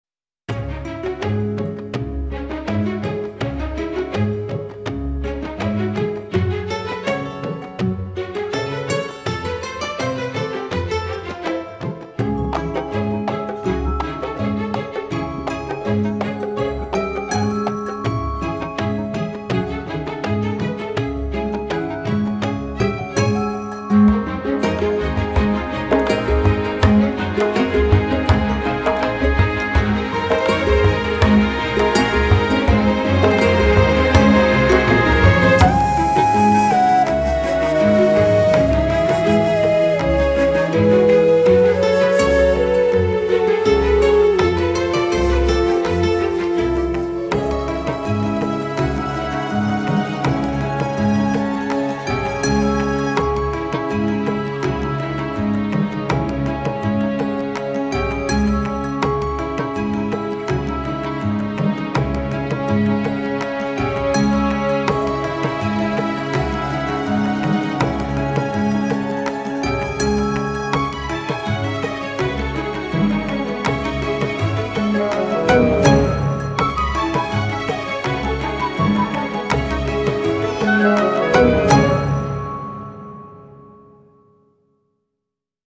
blends orchestral elements with Indian-inspired sounds